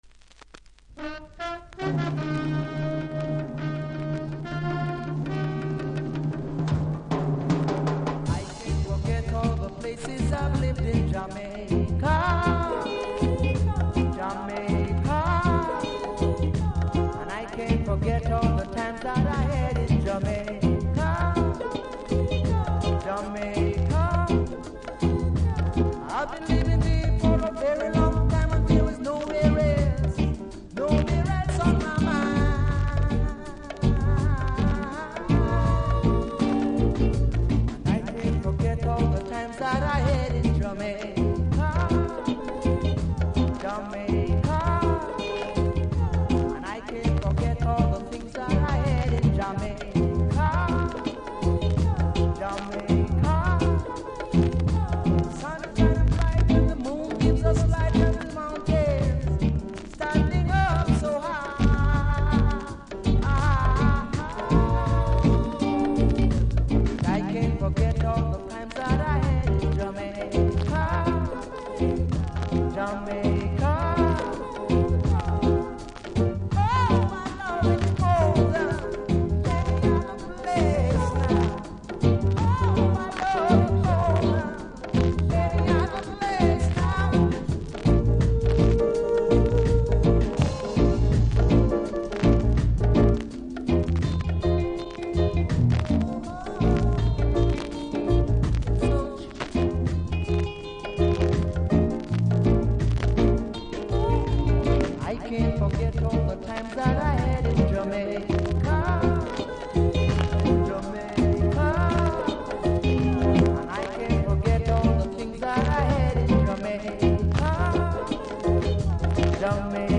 A6, B6プレス起因のジリジリとノイズ多めなのでVG-としました。